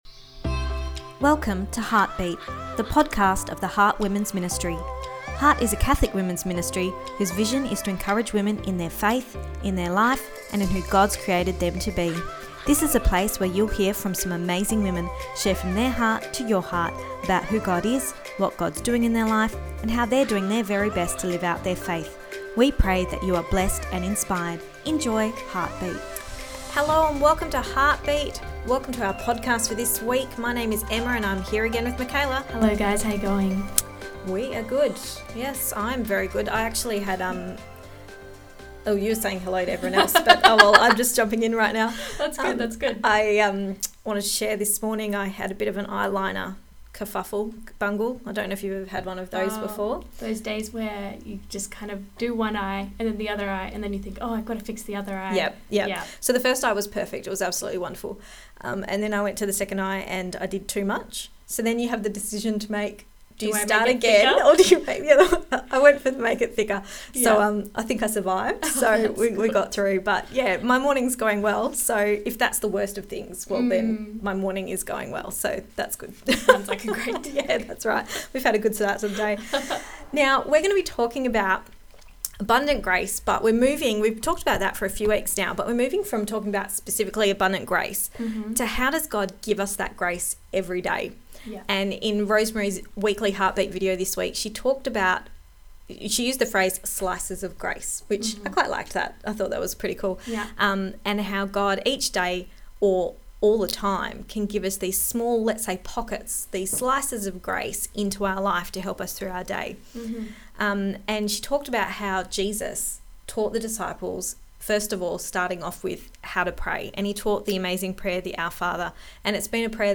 Episode 8 – Slices of Grace (Part 2 The Discussion)